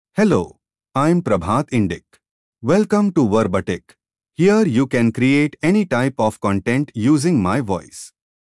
MaleEnglish (India)
Prabhat Indic is a male AI voice for English (India).
Voice sample
Male
Prabhat Indic delivers clear pronunciation with authentic India English intonation, making your content sound professionally produced.